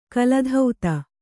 ♪ kaladhauta